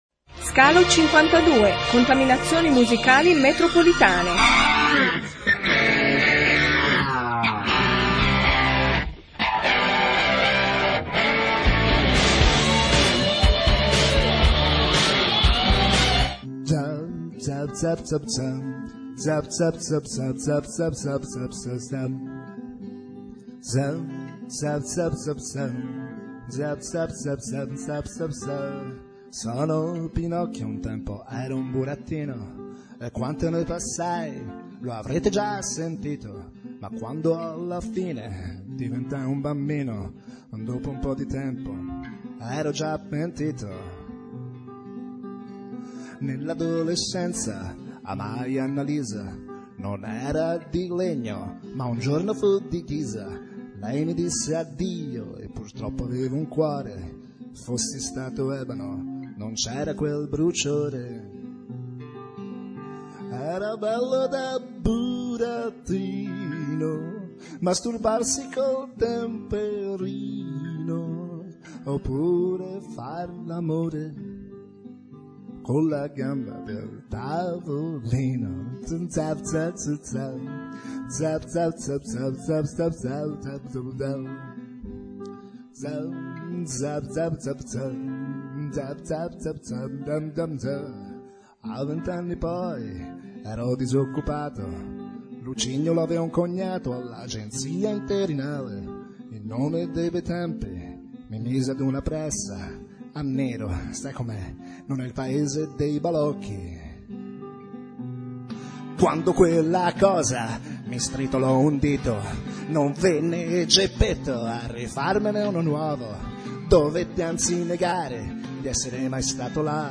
La registrazione integrale della mia intervista su Radio Insieme, emittente pratese.